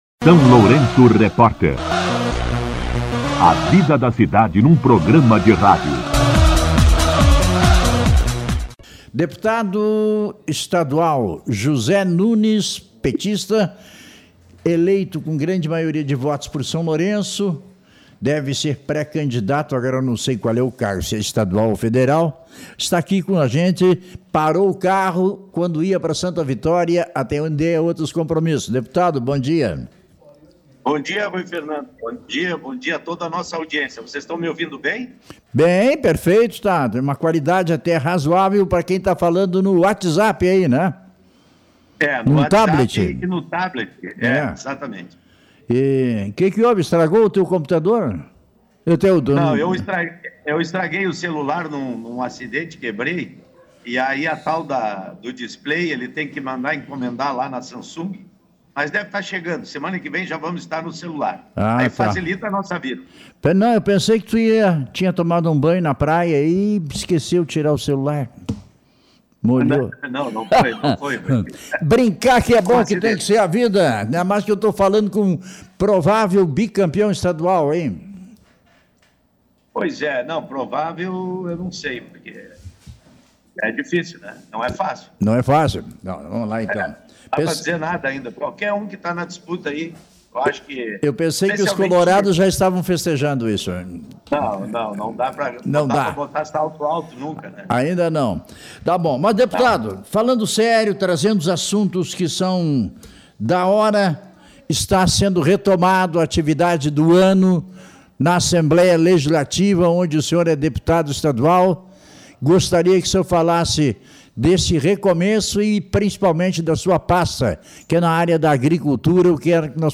Entrevista com O deputado estadual Zé Nunes
entrevista-19.02-ze-nunes.mp3.mp3